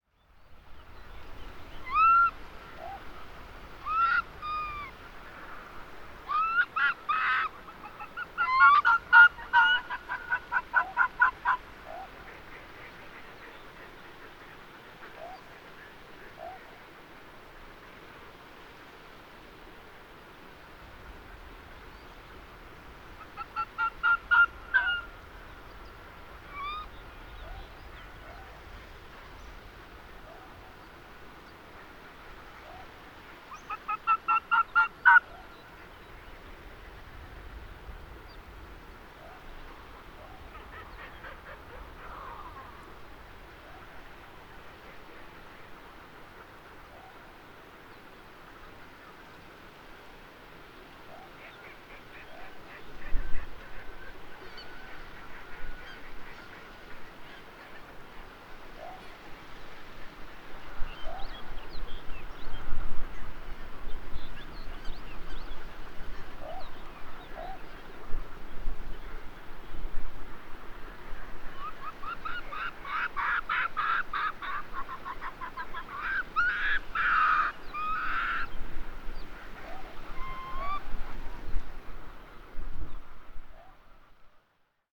Arctic Fox, male, female, calls
Hornbjarg, Iceland, Telinga parabolic reflector